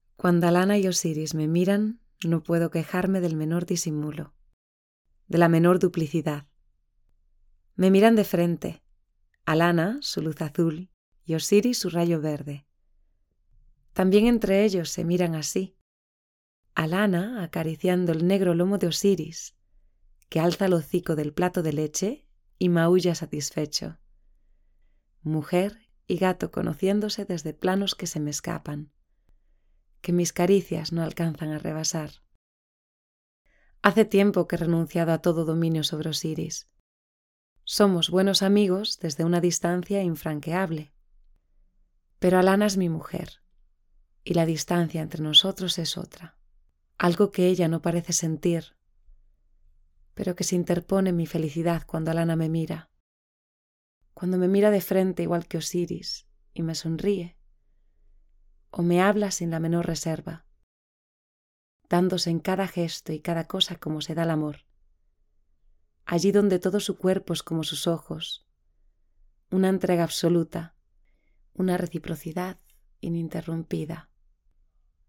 Female
Approachable, Assured, Character, Cheeky, Children, Confident, Conversational, Corporate, Energetic, Engaging, Friendly, Natural, Reassuring, Smooth, Soft, Upbeat, Versatile, Warm, Young
Spanish (native), French, Italian, Greek, Latino, London Multicultural.
Gaming Reel - English .mp3
Microphone: AKG C414 XL II
Audio equipment: Focusrite Scarlett 2i2, Portable booth.